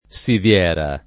siviera [ S iv L$ ra ]